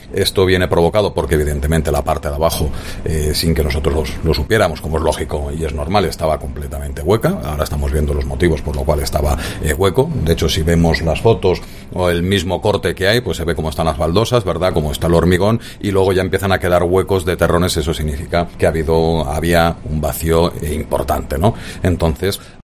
El Vicealcalde de Guadalajara, Jaime Carnicero, señala las causas del hundimiento